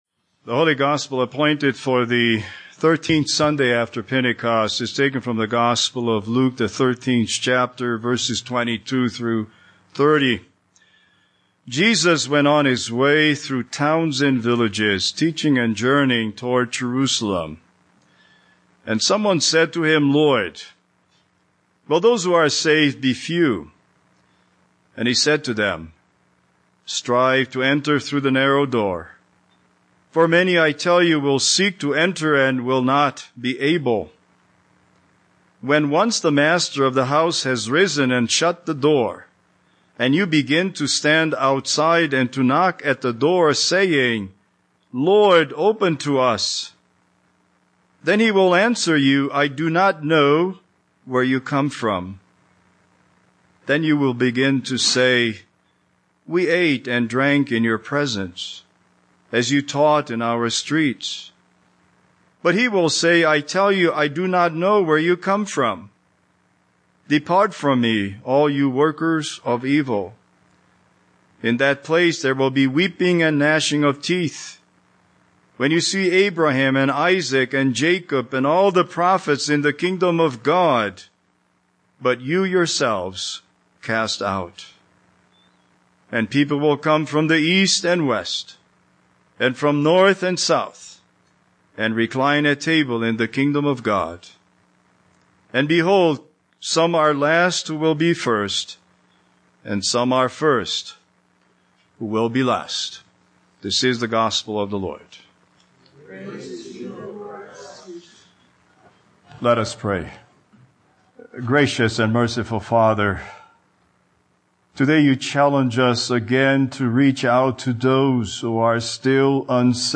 Speaker: Vacancy Pastor